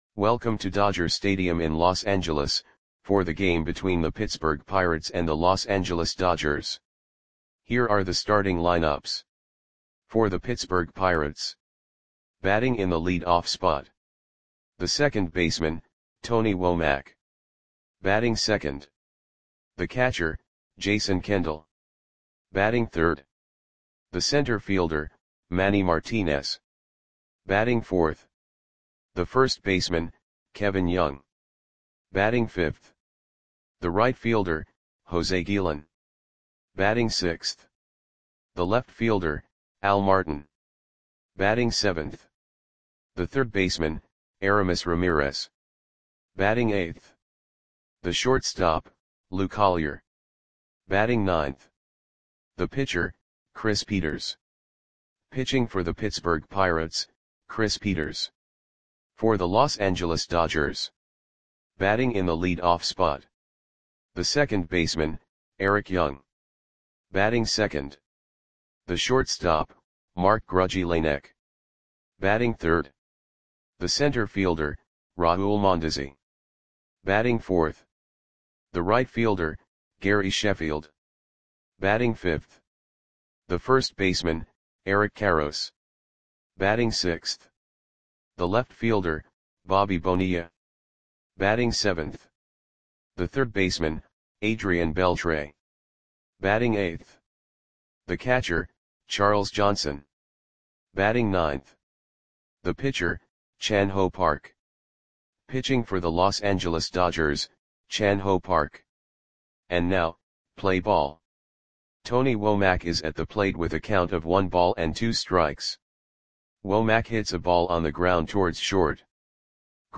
Click the button below to listen to the audio play-by-play.
Pirates 2 @ Dodgers 1 Dodger StadiumAugust 9, 1998 (No Comments)